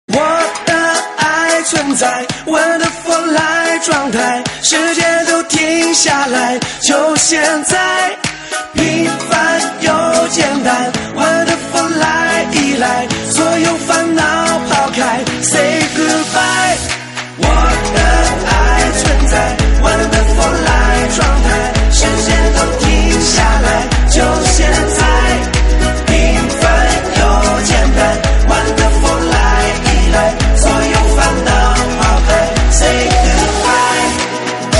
M4R铃声, MP3铃声, 华语歌曲 34 首发日期：2018-05-14 21:47 星期一